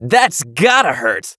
hotshot_kill_03.wav